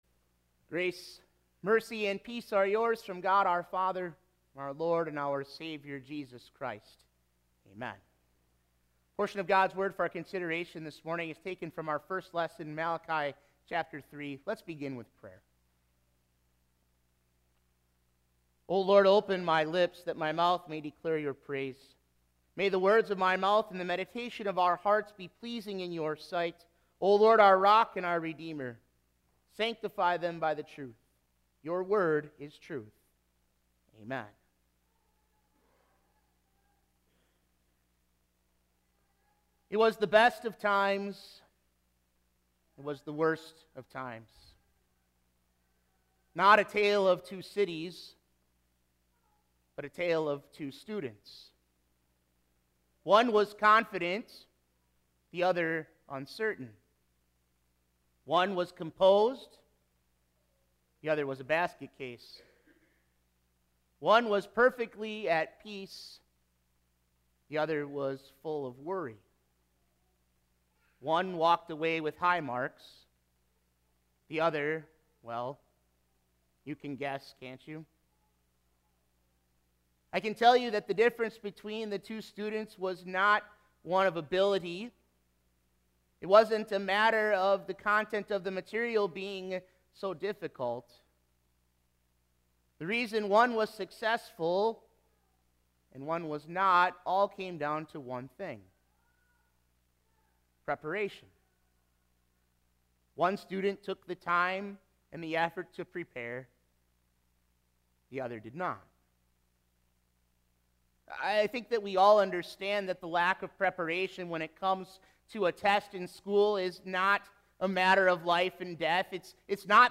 AudioSermonDecember92018.mp3